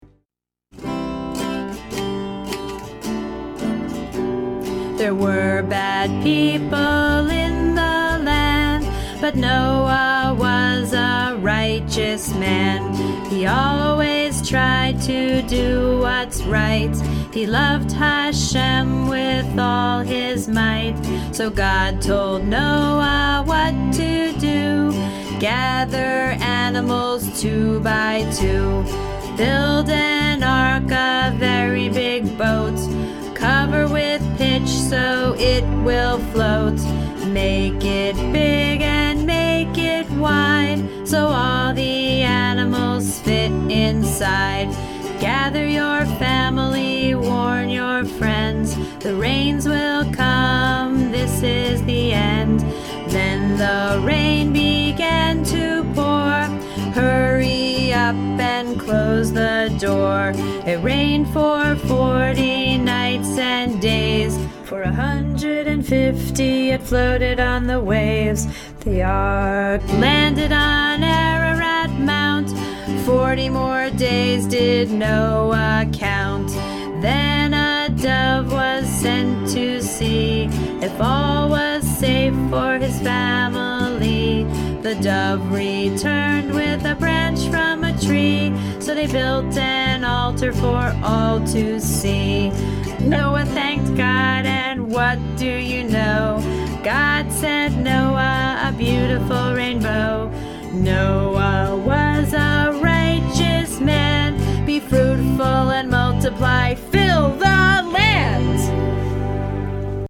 we are preschool teachers, not professional singers )